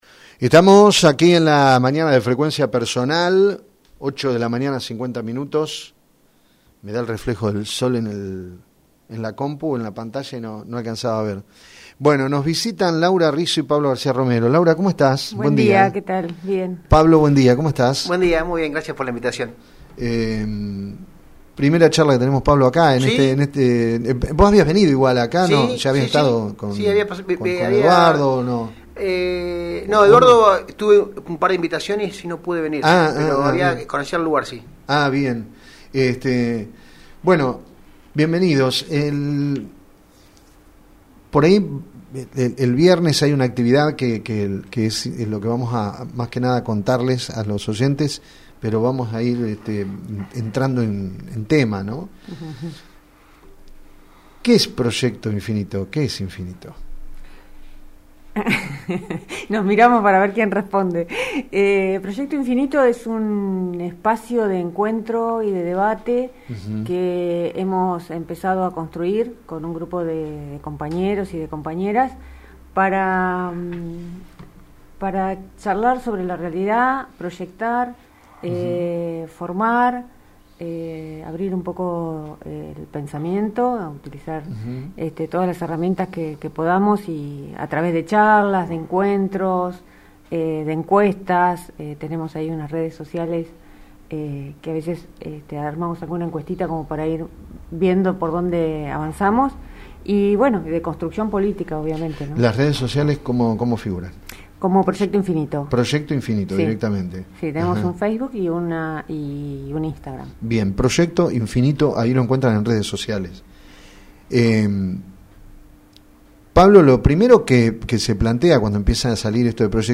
dos de los integrantes de este nuevo espacio generado en nuestra ciudad, estuvieron en Play Radios contando como surgió el espacio, cuales son los proyectos que tienen y como se desarrollará este nuevo encuentro, abierto a la comunidad.